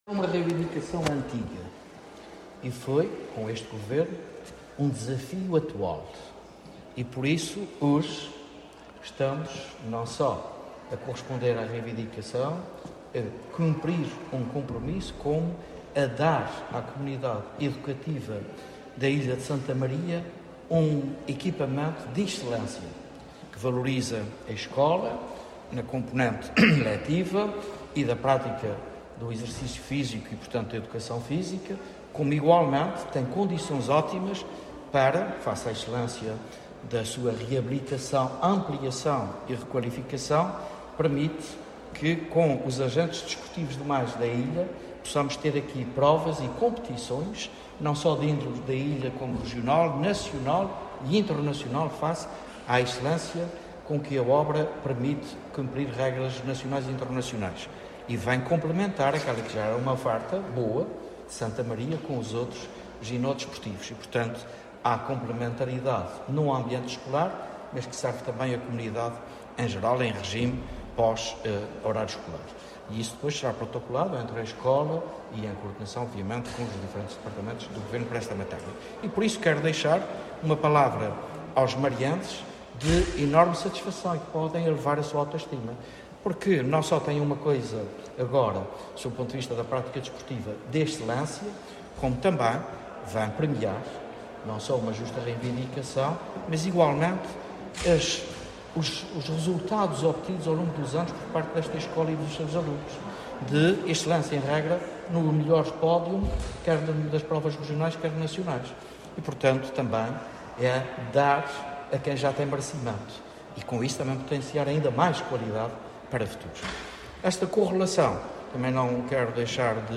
O Presidente do Governo Regional dos Açores, José Manuel Bolieiro, presidiu hoje à inauguração da requalificação do pavilhão gimnodesportivo da Escola Básica e Secundária de Santa Maria, valorizando na ocasião a concretização de uma “reivindicação antiga” que foi cumprida por este Executivo.